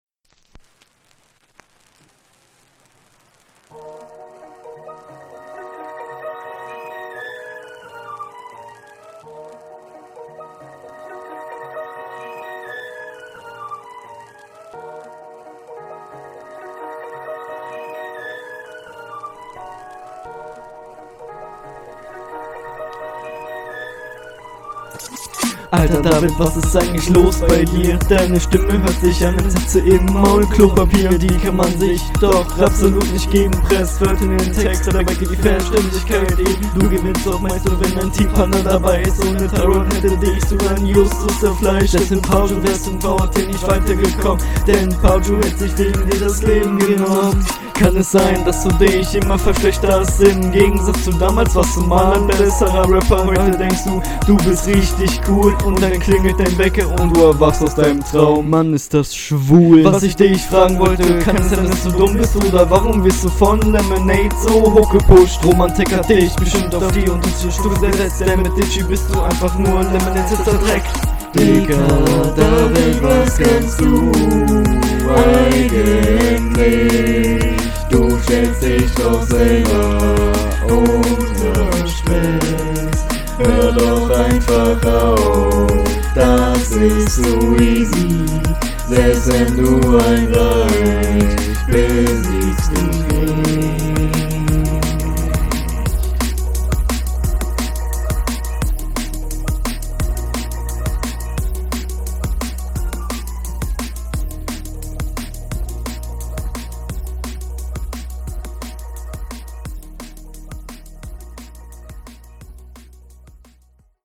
90s oldschool boombap mit techno okay.